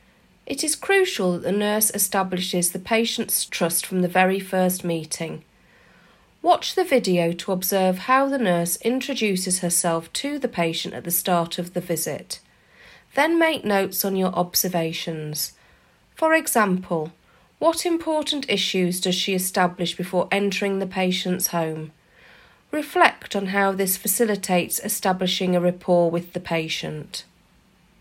Watch the video to observe how the nurse introduces herself to the patient at the start of the visit.